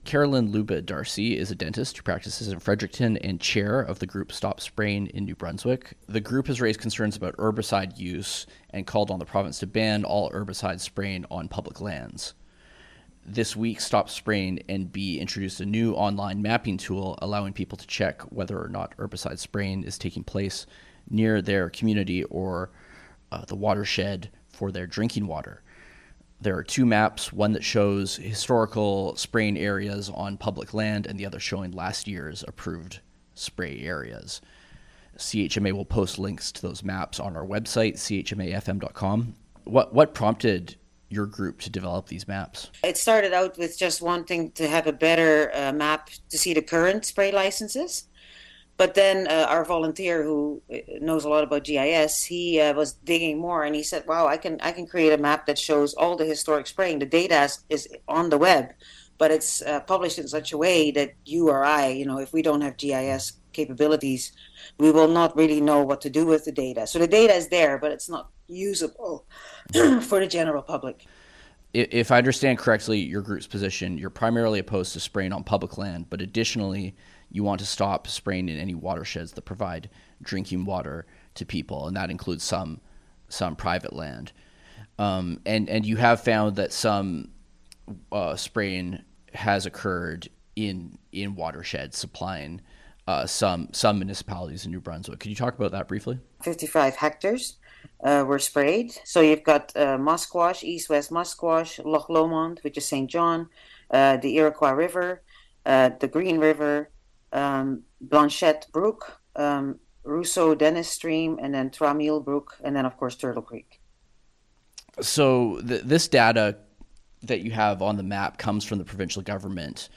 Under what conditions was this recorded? CHMA - Sackville